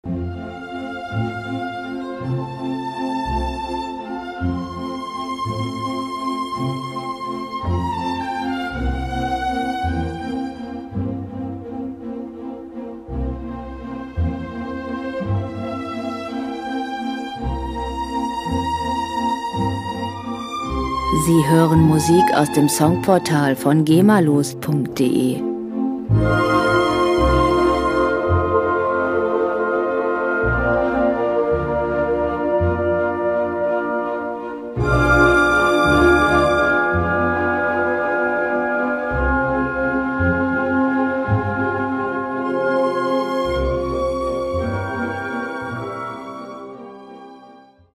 Klassische Musik - Perlen der Klassik
Musikstil: Klassik
Tempo: 83 bpm
Tonart: F-Dur
Charakter: ruhig, entspannend
Instrumentierung: Orchester, Klavier